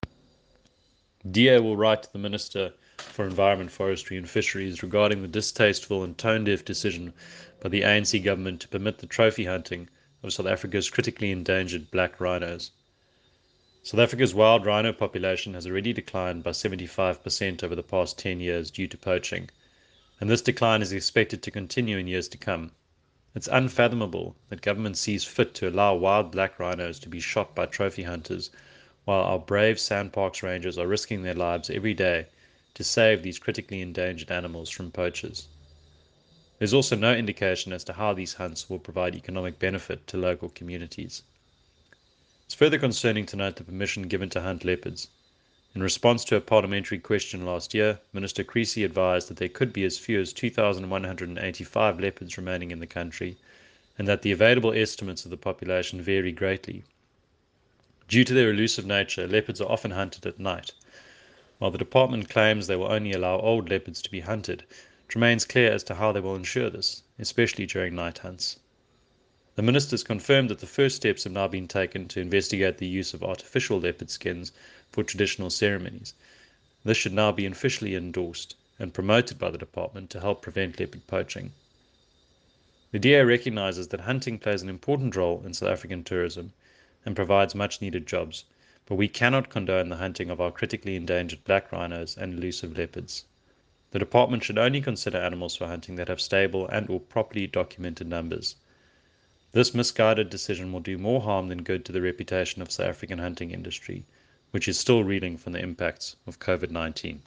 soundbite by Dave Bryant MP.